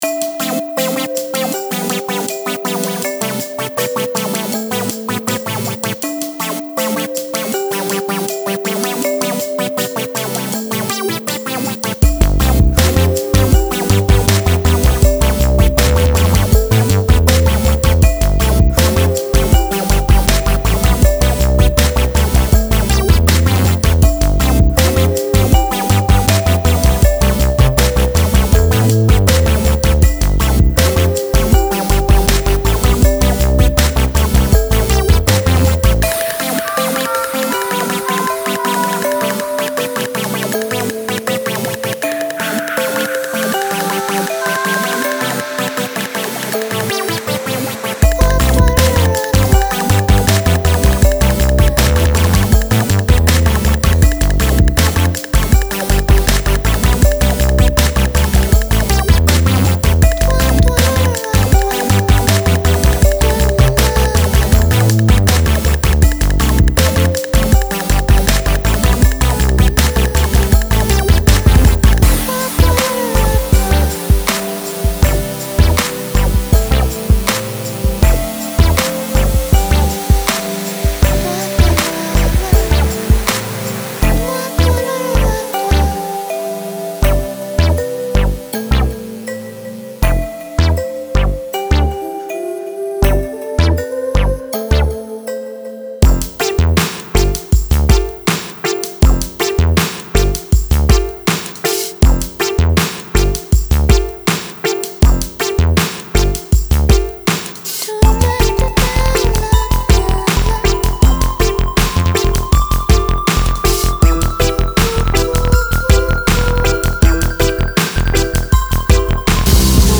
Style : POPS